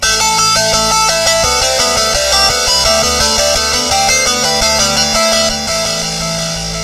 رنة هاتف اجنبية موسيقى حماسية قصيرة عالية الصوت